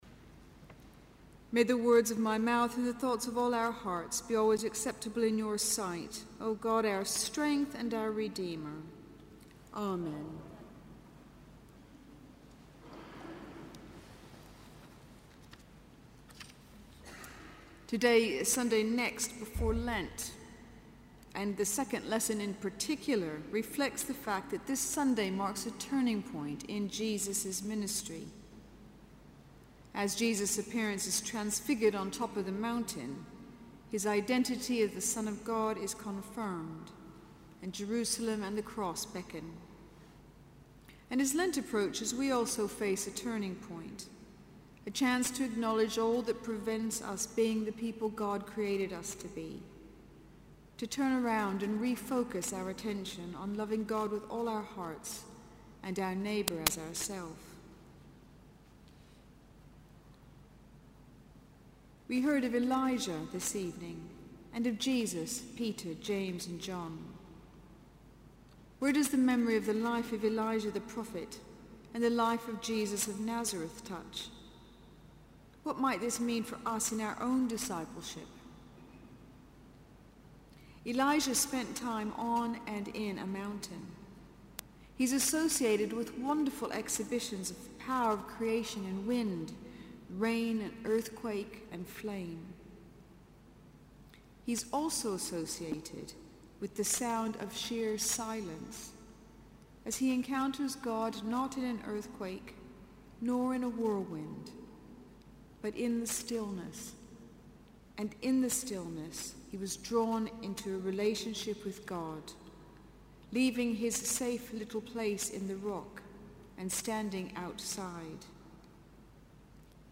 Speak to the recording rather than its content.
Guildford Cathedral - Sermons